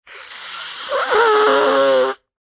Звуки сморкания
Звук сморкающегося человека